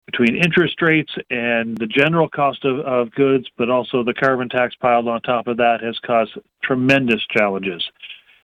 He finished the interview by thanking his staff for the work with residents in the riding.